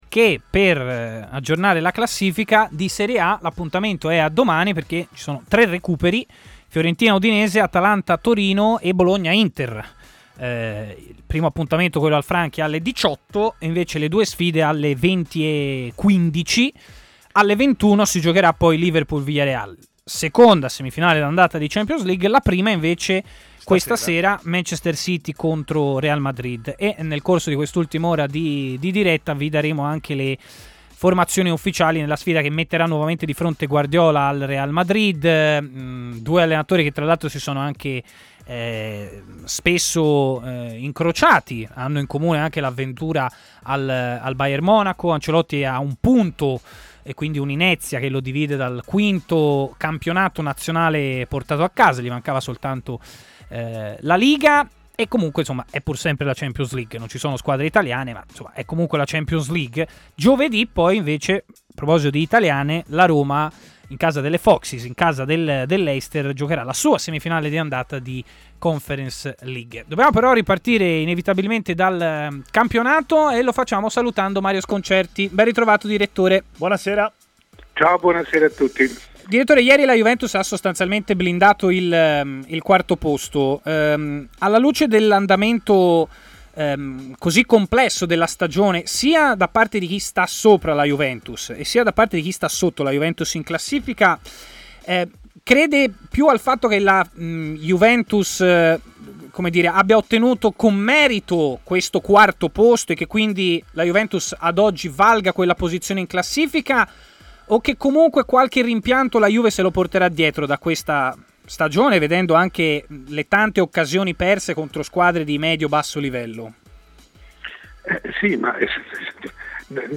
Le Interviste
Ascolta l'audio Mario Sconcerti , decano del giornalismo sportivo e opinionista, è intervenuto ai microfoni di Tmw Radio . Cos'è successo al Napoli in tre giornate?